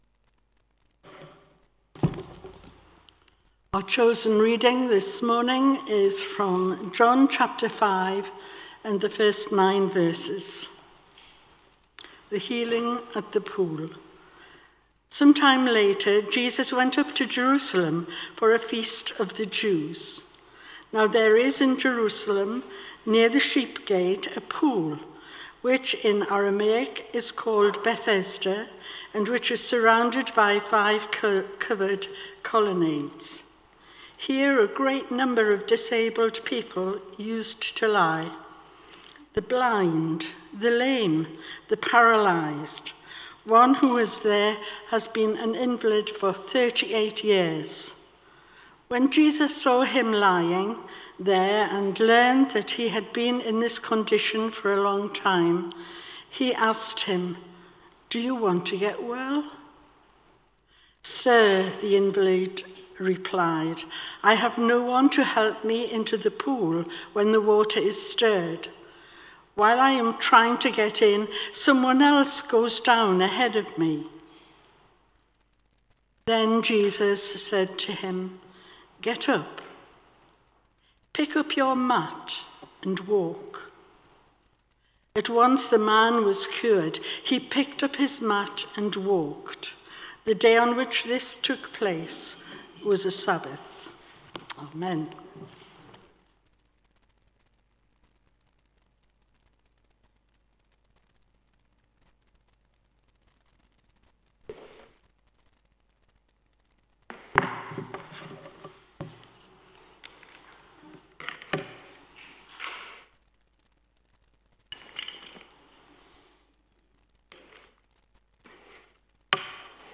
16 Tagged with Morning Service , Guest Speaker Audio